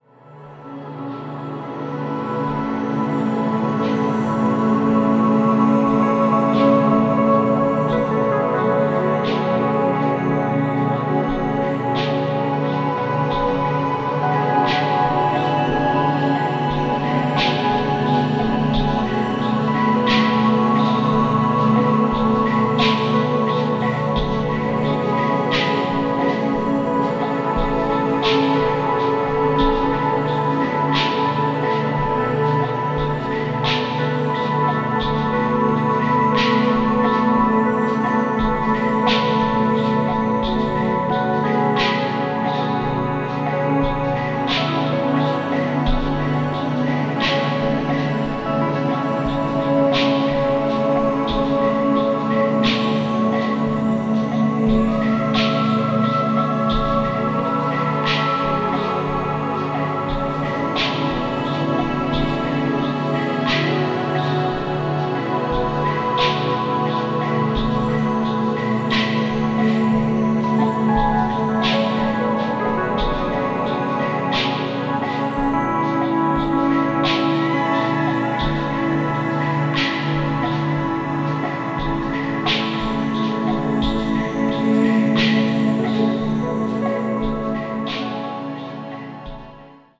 豊かに反復するドローン、細やかなピアノの残響、丹念に作り上げられた音像と１曲１曲がドラマチックに満ちてゆく。